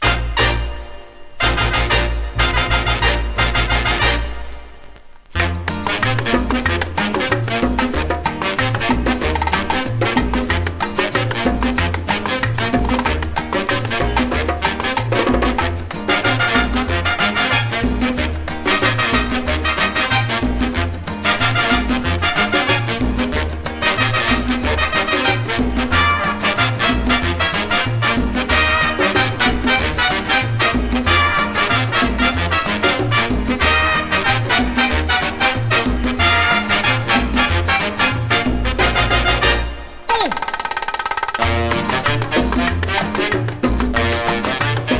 キレッキレで迫力ある音と楽曲の親しみやすさ、普遍とも感じるこの楽しさに嬉しくなります。
Latin, World　USA　12inchレコード　33rpm　Stereo